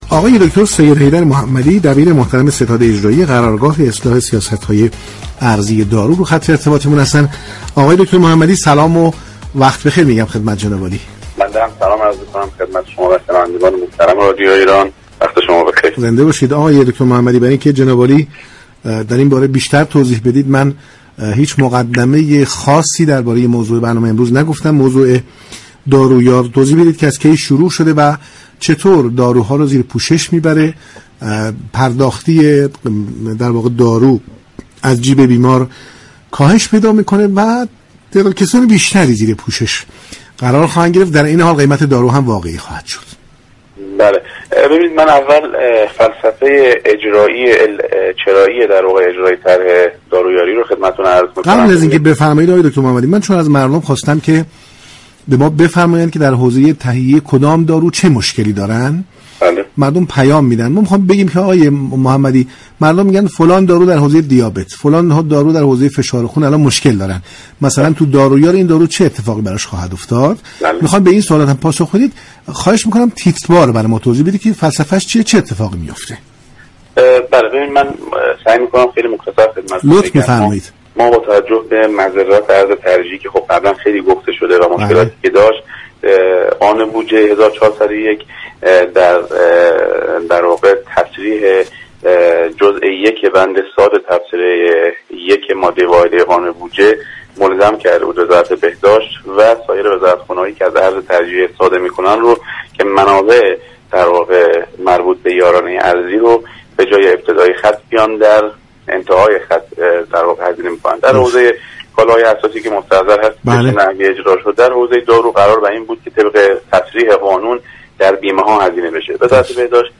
برنامه ایران امروز شنبه تا سه شنبه هر هفته ساعت 11:45 از رادیو ایران پخش می شود.